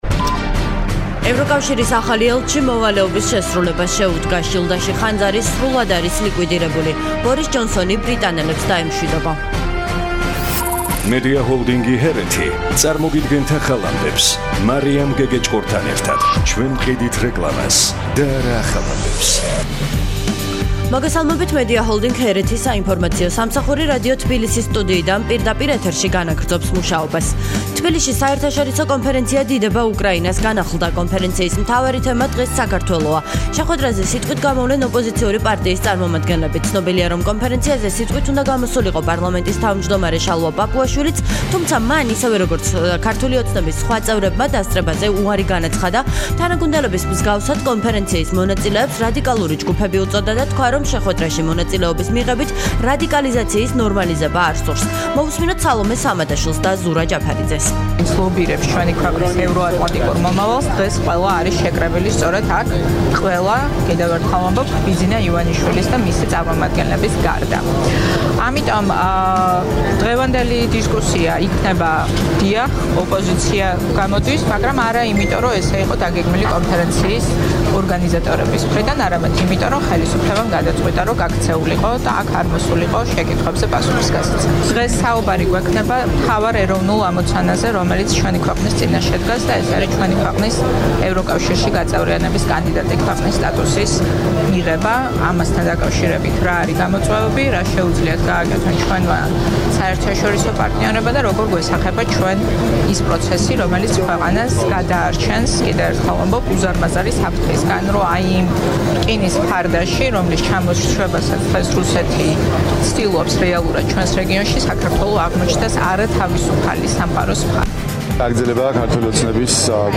ახალი ამბები 12:00 საათზე – HeretiFM